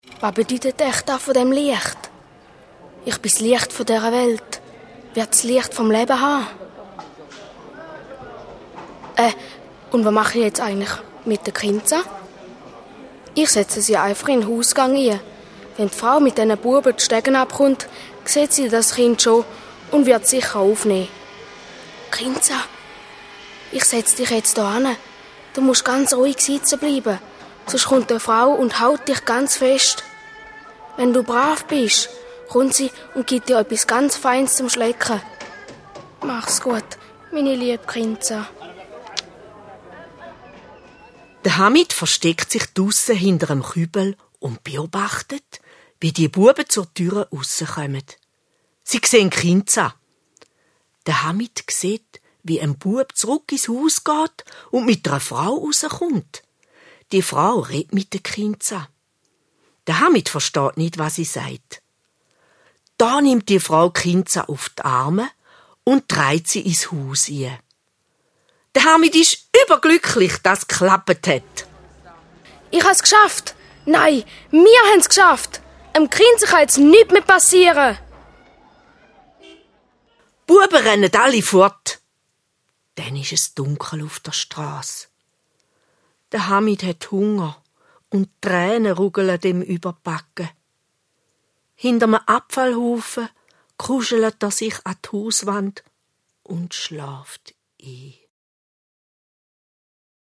Musikalisches Hörspiel
Ein musikalisches Hörspiel: Mit 10 eingängigen Songs!